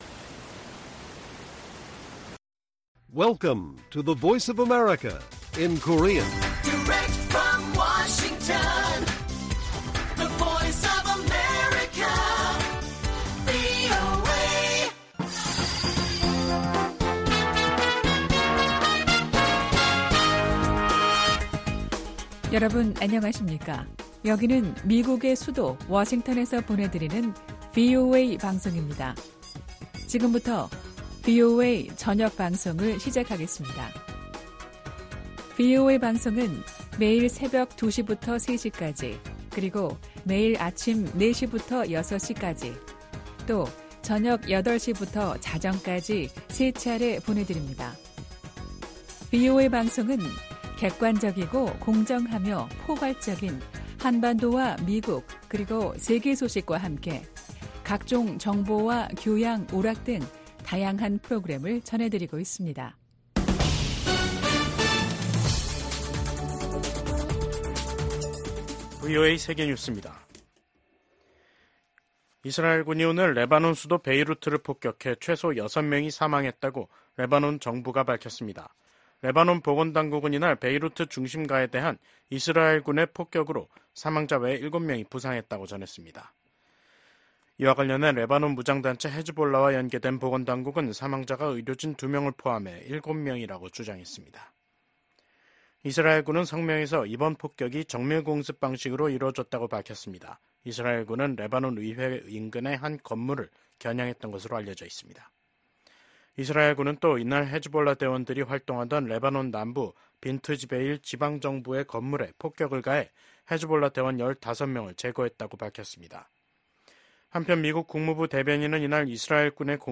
VOA 한국어 간판 뉴스 프로그램 '뉴스 투데이', 2024년 10월 1일 1부 방송입니다. 미국 국무부의 커트 캠벨 부장관은 러시아 군대의 빠른 재건이 중국, 북한, 이란의 지원 덕분이라고 지적했습니다. 조 바이든 미국 대통령이 새로 취임한 이시바 시게루 일본 총리와 처음으로 통화하고 미한일 협력을 강화와 북한 문제를 포함한 국제 정세에 대응하기 위한 공조를 재확인했습니다.